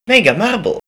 pumegamarblevoice.wav